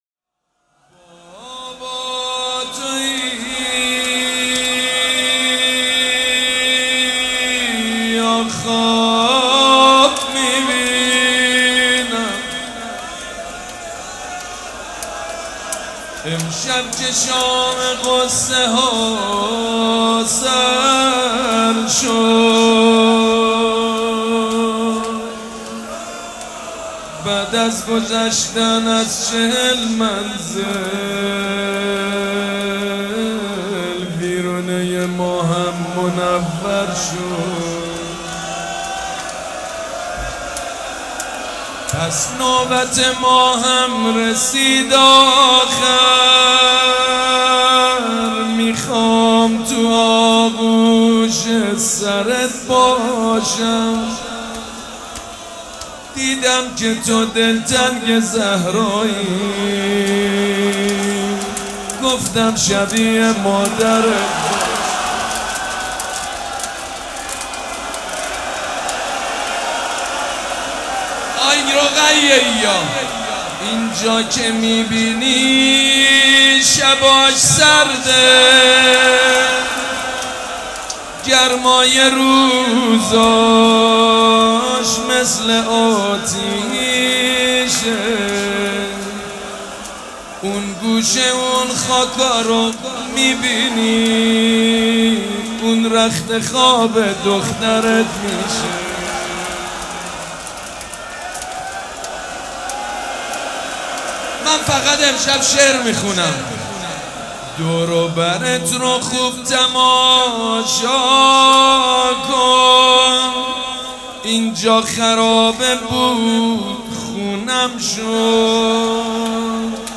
روضه شب سوم مراسم عزاداری صفر